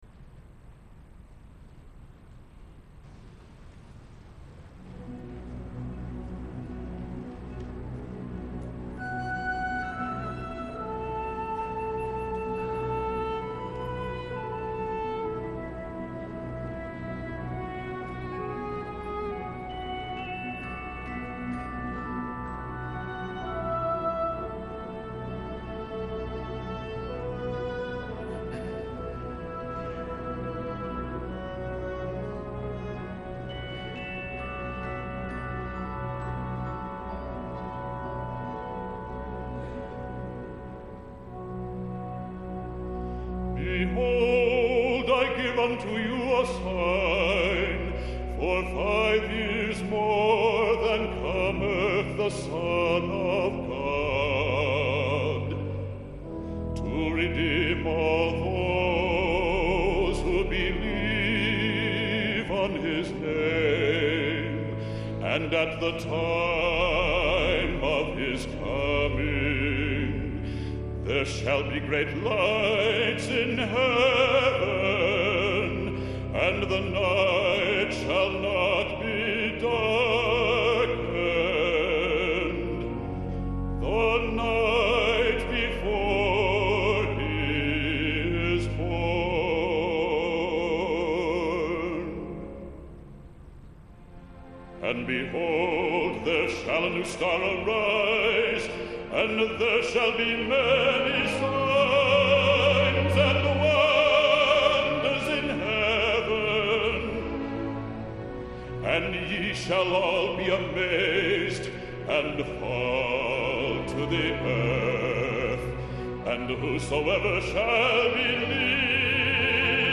Leroy J. Robertson:  Oratorio from the Book of Mormon